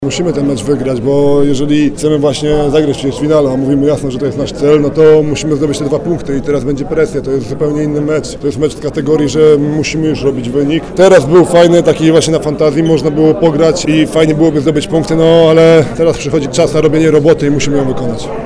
Mówi Tomasz Gębala, rozgrywający reprezentacji Polski: